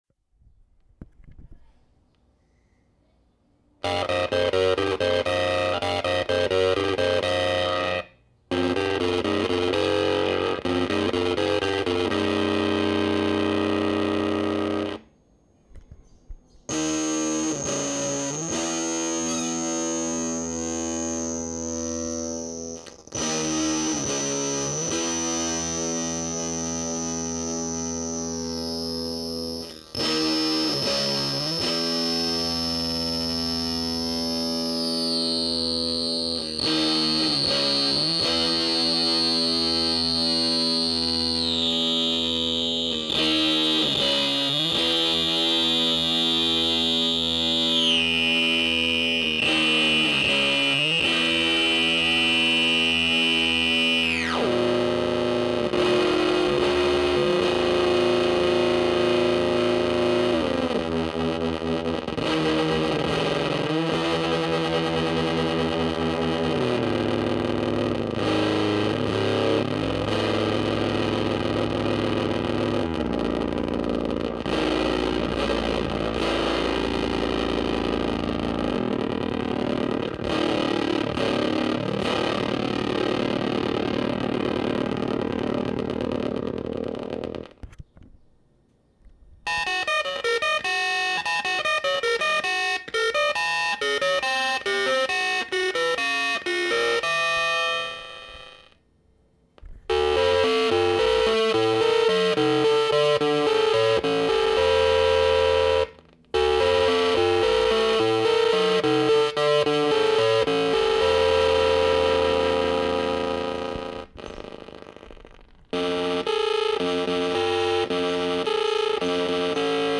The sound is more similar to the android than the planetoid, but it has more organic full sound than the android.
here is just the robotum, quick video, there are some birds and chair noise and sloppy playing, since this was quick (the sloppy will also stay :P)